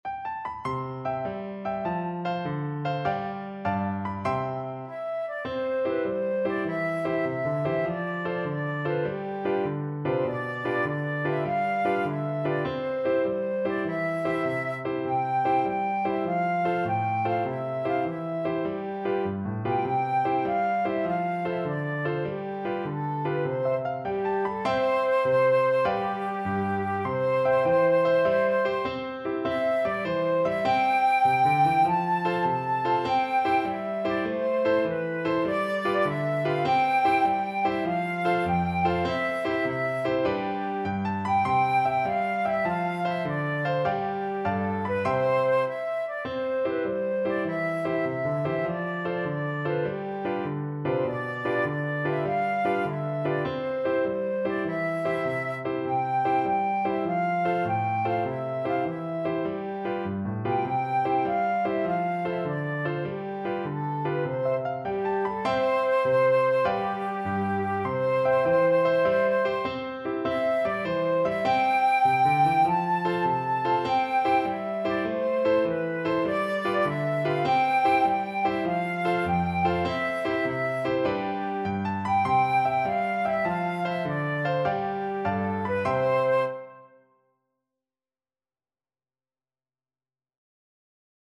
Flute
C major (Sounding Pitch) (View more C major Music for Flute )
Allegro .=c.100 (View more music marked Allegro)
6/8 (View more 6/8 Music)
Traditional (View more Traditional Flute Music)